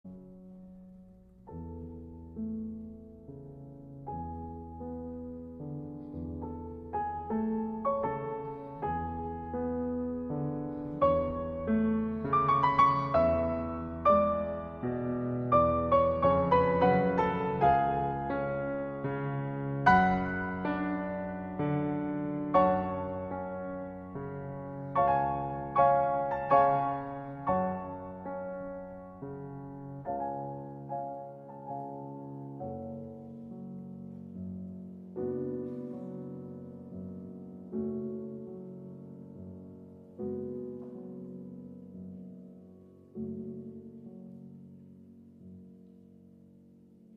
يُعزف مره أخرى نفس اللحن ولكن بمصاحبة هارموني مختلف؛ A major المفتاح المقابل للماينرF#، ذلك الهارموني أوحى بمشاعر السكينة برغم كئابة اللحن، كمن وجد الصديق المناسب ليشكو له همومه
بعد ذلك يتضاعف كل ماسبق بإلحاح بزيادة اوكتف، ينتهي بذلك الجزء أ